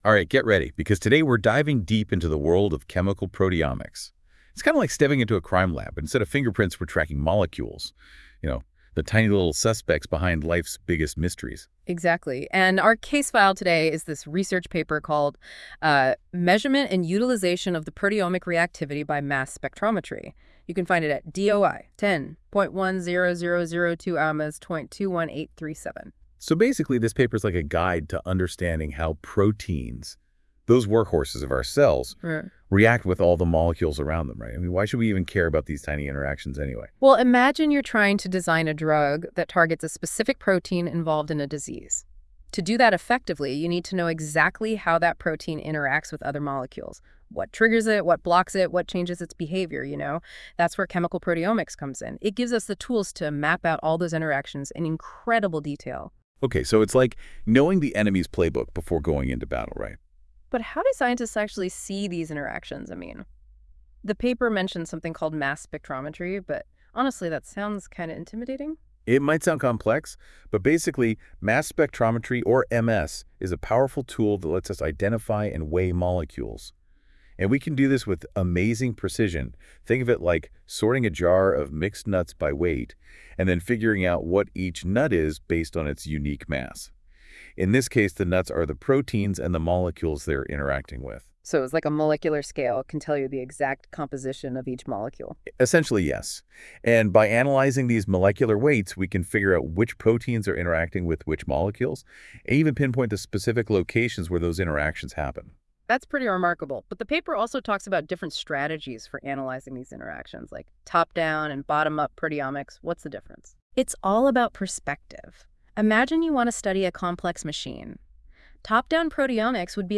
“ Measurement and utilization of the proteomic reactivity by mass spectrometry. ” Punzalan, C.; Wang, L.; Bajrami, B.; Yao, X. Mass Spectrometry Reviews, 2024, 43:166–192; DOI:10.1002/mas.21837. Podcast by Google Generative AI.